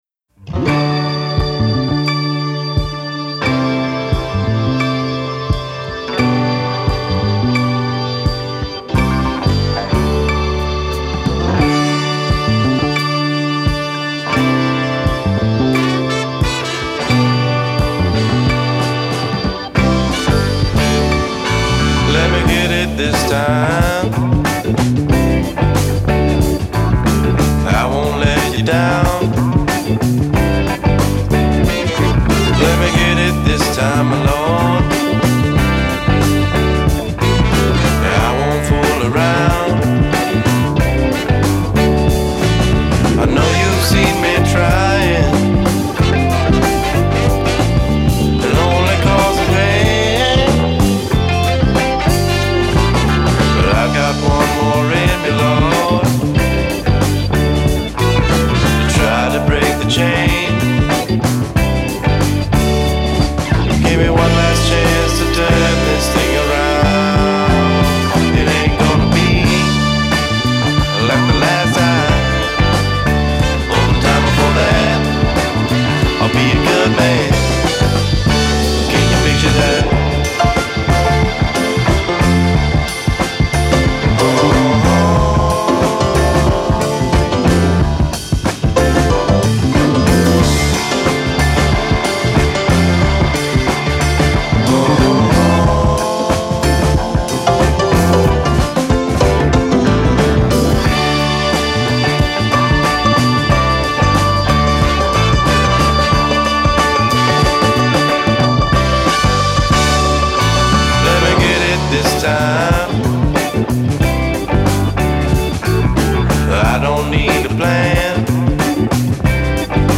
Cocktail rétro soul et savoureux
un album rétro pop-funk-soul du plus bel effet